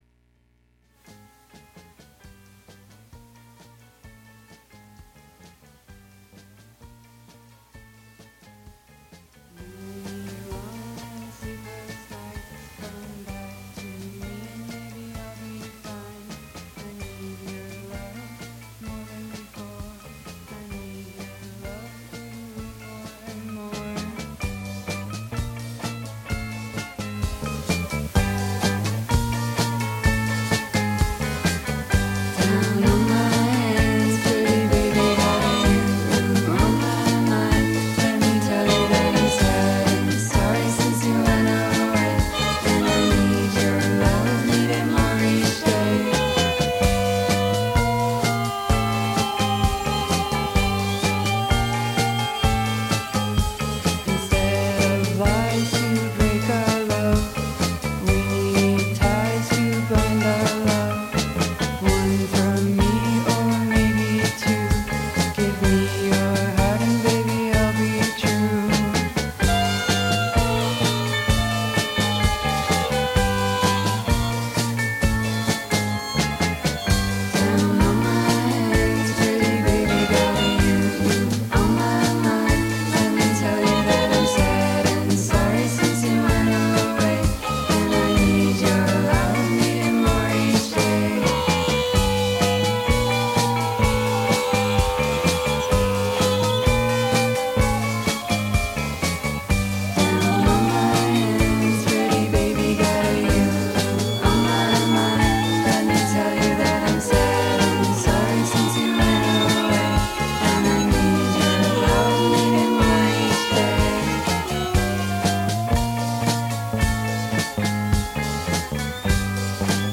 Coupla tech snafus.